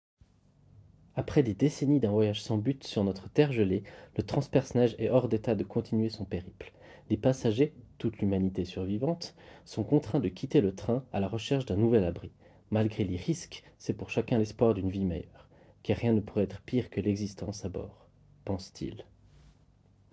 Conduction osseuse
En effet, exit la technologie classique, ici on parle de capteurs de conduction osseuse qui capturent la voix à partir des vibrations des os du crâne du porteur. Sans aller dans des détails inutiles, voici le rendu enregistré par les écouteurs.